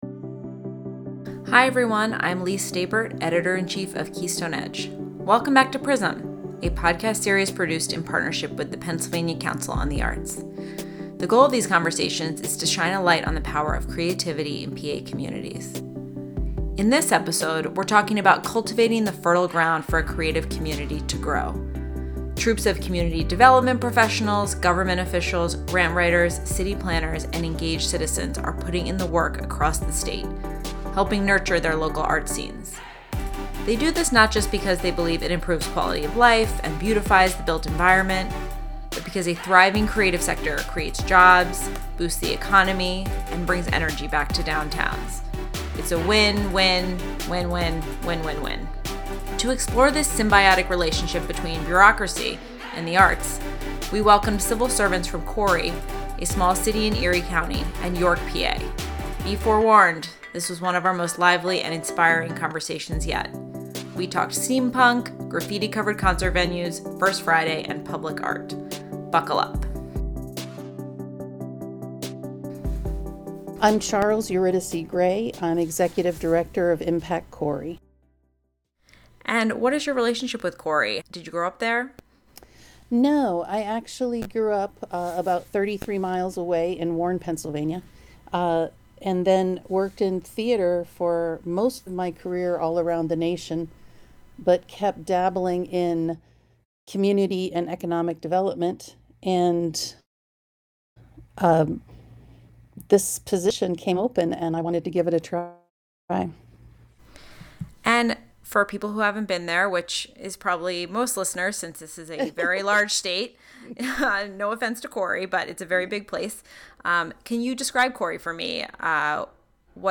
In a lively conversation, we heard from two such folks, one from Corry and one from York, about how their communities support, fund, and catalyze the creative sector, and how that work is paying unexpected dividends.
Be forewarned: This was one of our most lively and inspiring conversations yet. We talk steam punk, graffiti-covered concert venues, first friday, and public art.